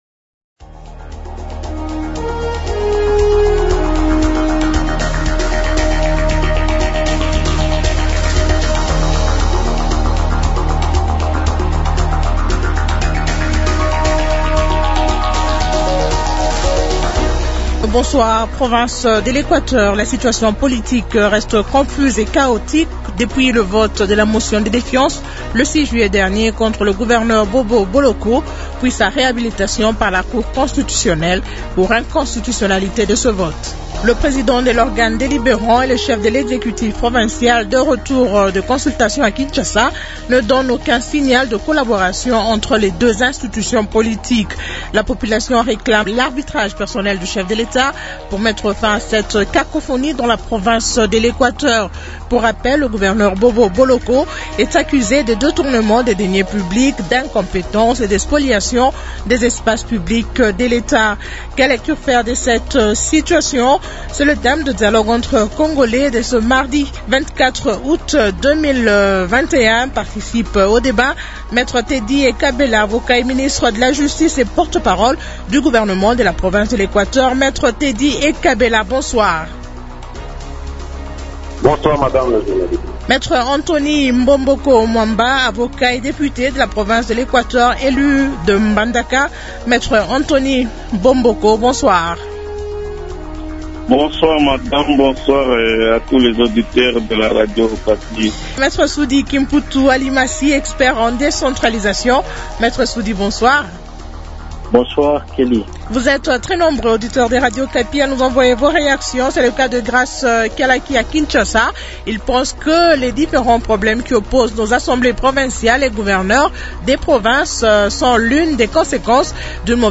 Invités Me Teddy Ekabela, avocat et ministre de la Justice et porte-parole du gouvernement de la province de l’Equateur Me Antony Bomboko Mwamba, avocat et député de la province de l’Equateur élu de Mbandaka
expert en décentralisation